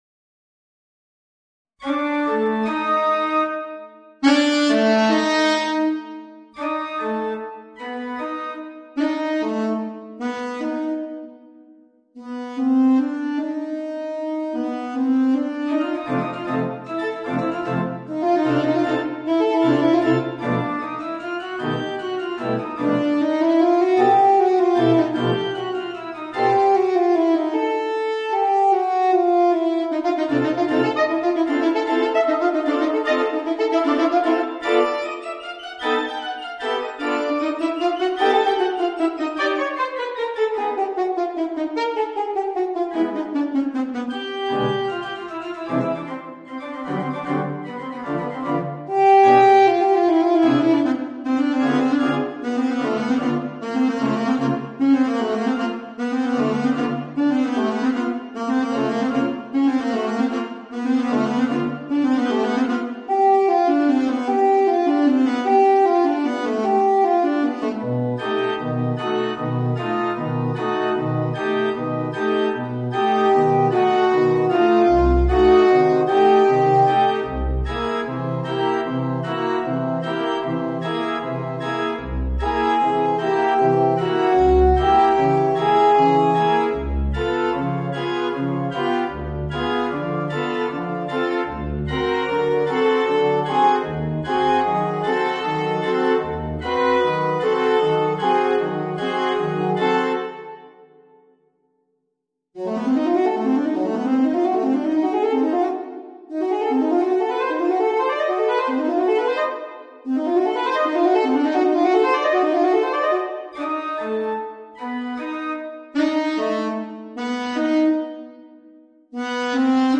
アルトサックス+ピアノ